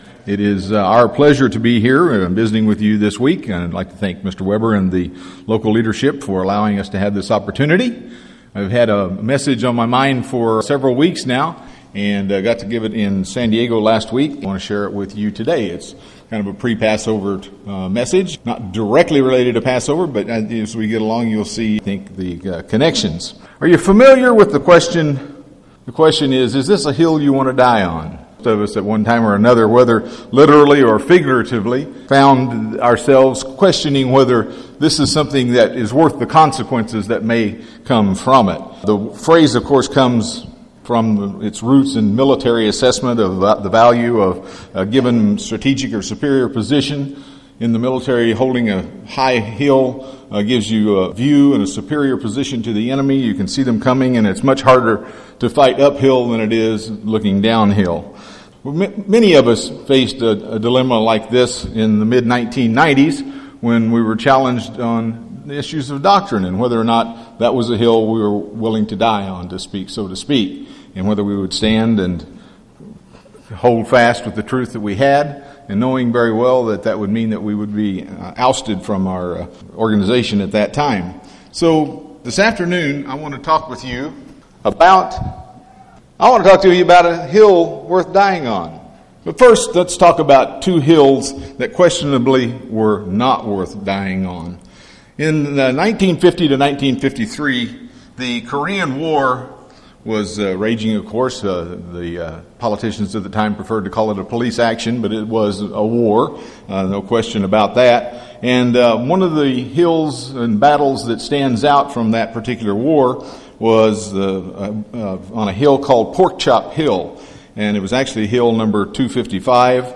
John 19:17 UCG Sermon Studying the bible?